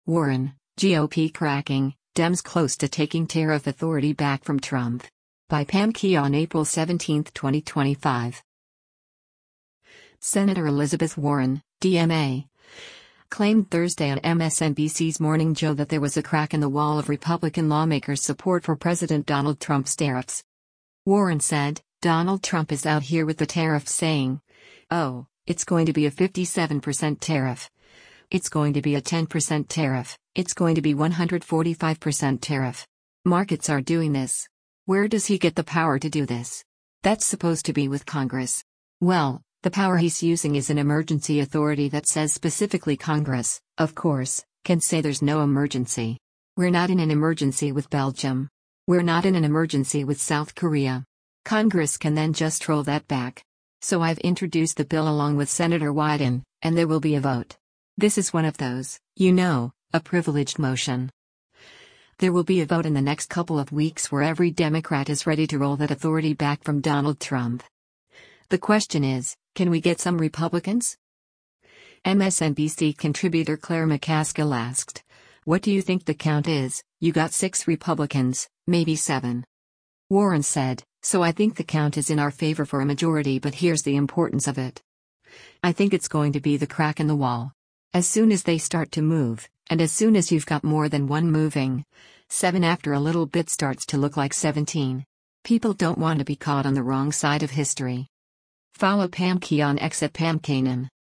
MSNBC contributor Claire McCaskill asked, “What do you think the count is, you got six Republicans, maybe seven?”